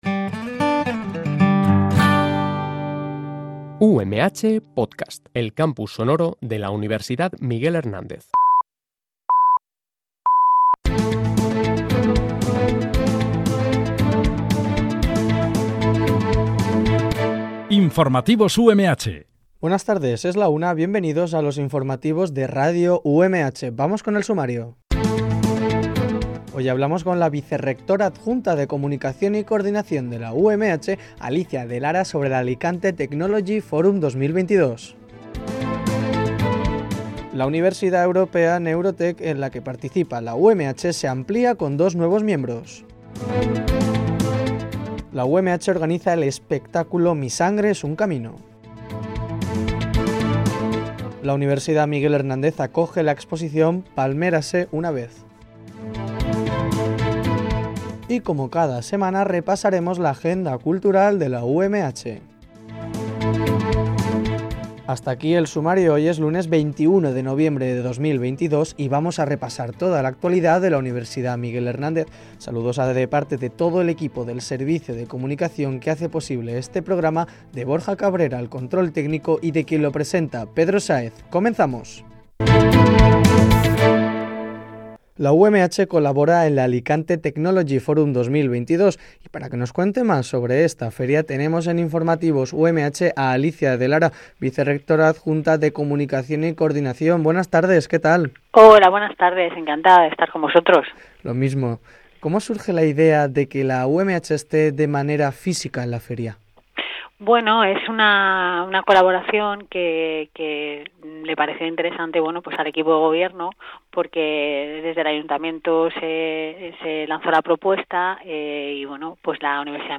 Este programa de noticias se emite de lunes a viernes, de 13.00 a 13.10 h